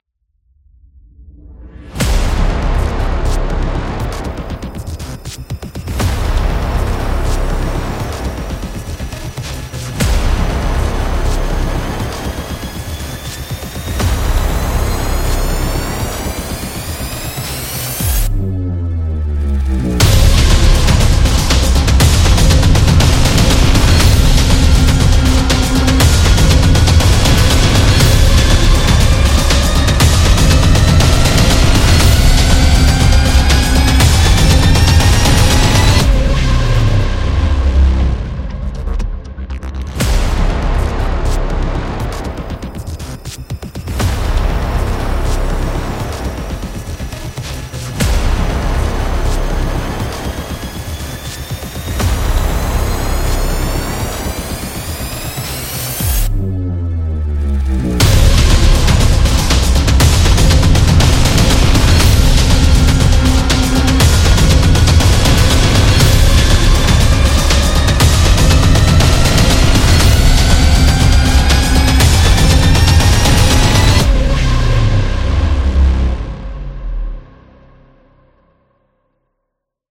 Cinematic